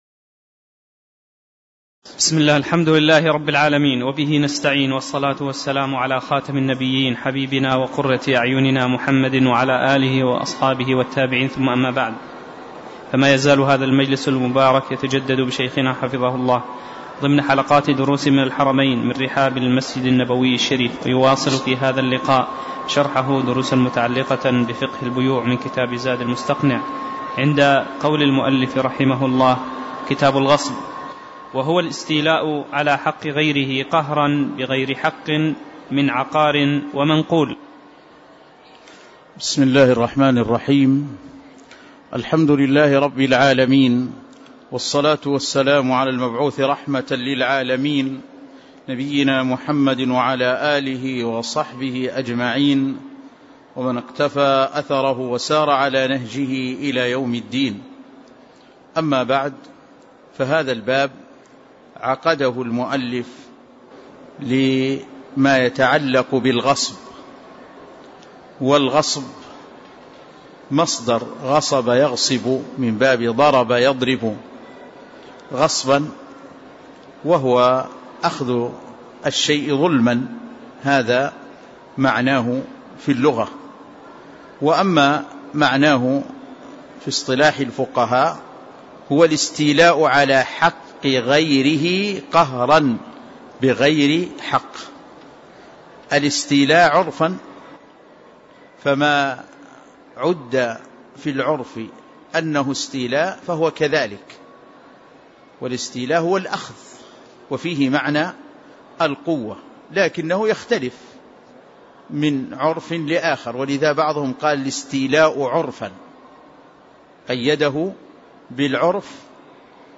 تاريخ النشر ١٢ صفر ١٤٣٧ هـ المكان: المسجد النبوي الشيخ